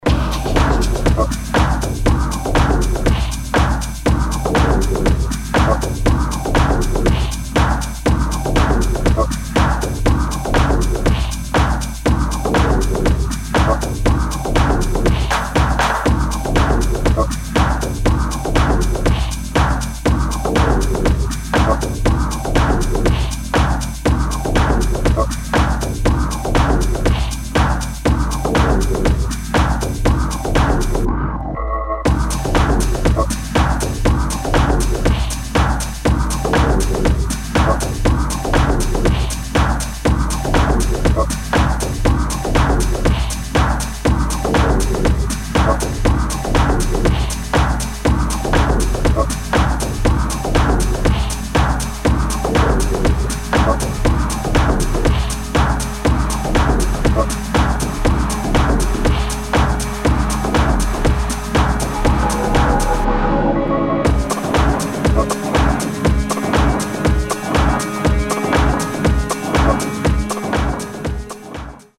[ HOUSE / BASS / TECHNO ]